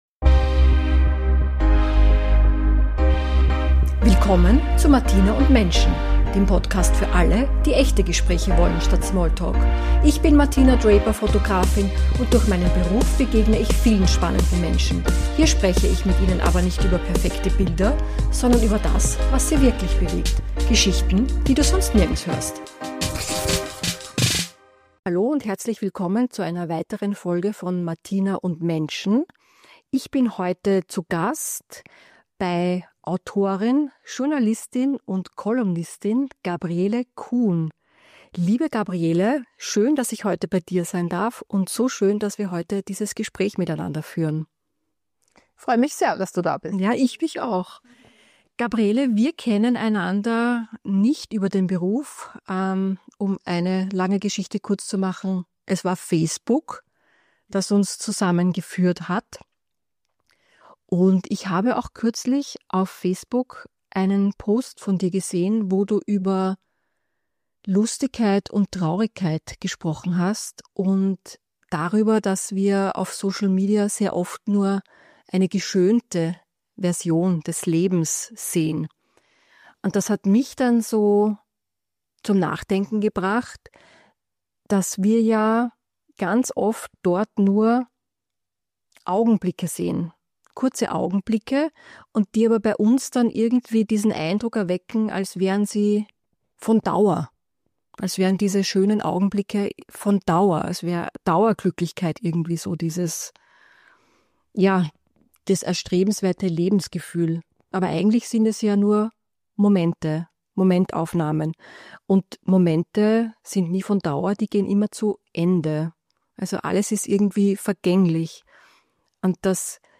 Wir reden über transgenerationale Traumata, Sterbebegleitung, Abschiedsrituale und die Sprachlosigkeit, die immer noch beim Thema Tod vorherrscht. Ein Gespräch über Trauer und Verluste, die ganz natürlich zu unserem Leben dazugehören und endlich entstigmatisiert werden müssen.